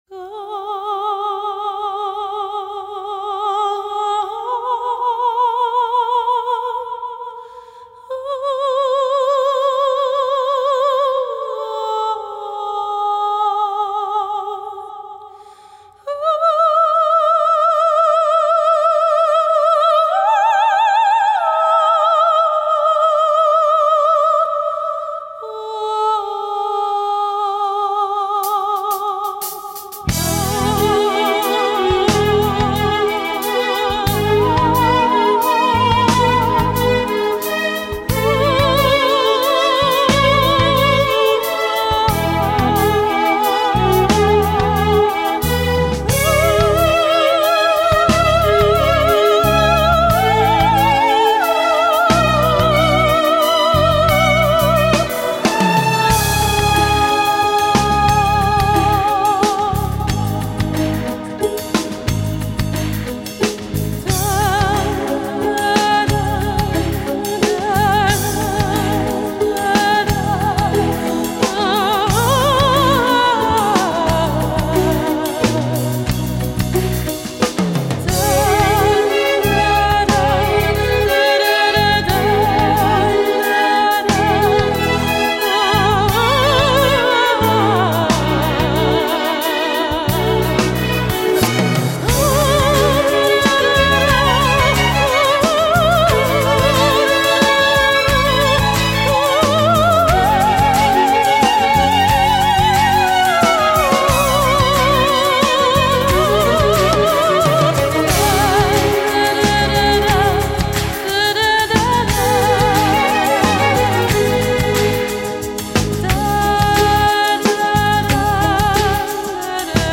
橫跨流行古典、加拿大英法語雙聲跨界音樂新天后
上帝賜予水晶般清澄透澈嗓音獨樹一格
● 這張結合聲樂美學，回歸新世紀空靈，更勝流行音樂質感的誠意專輯，是眾多商業包裝中難得一見清新佳作。